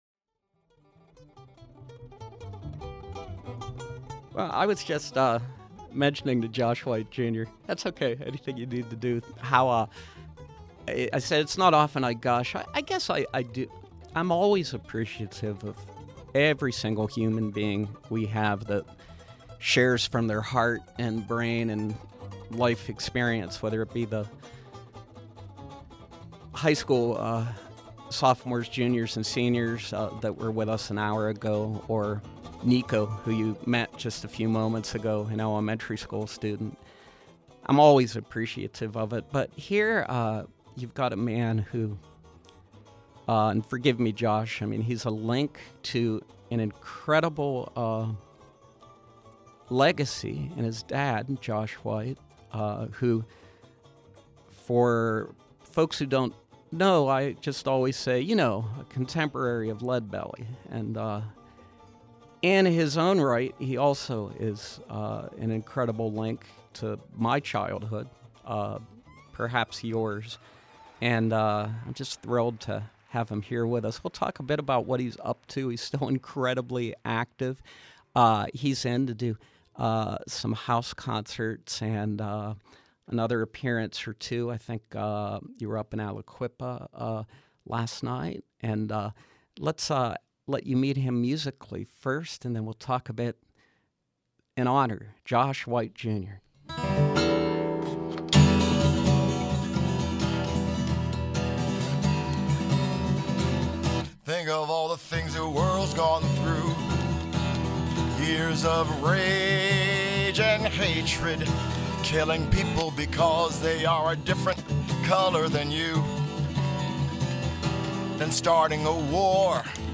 acoustic bluesman